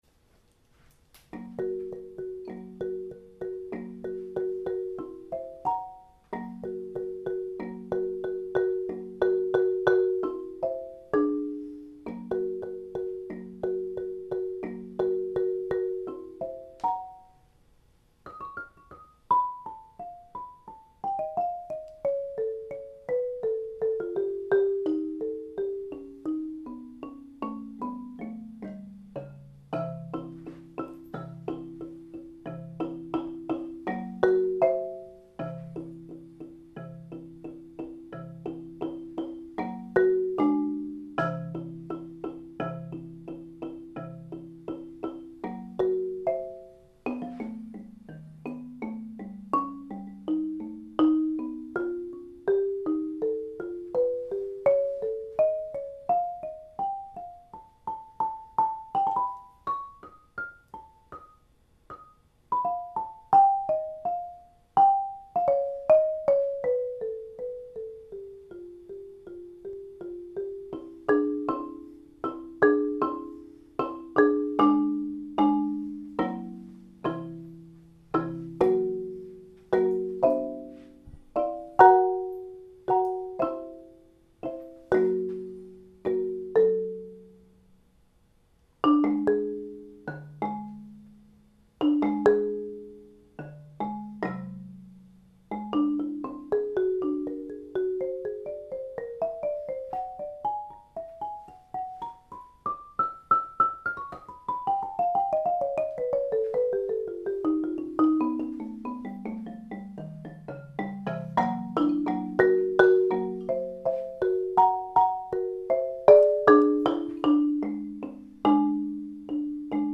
Here's a track recorded with the back mics (120 degrees) as WAV, then normalized and MP3 encoded by the recorder itself:
It's my marimba without the resonators (late night practice mode). It captured the sound and the stereo image of the instrument pretty well, I think.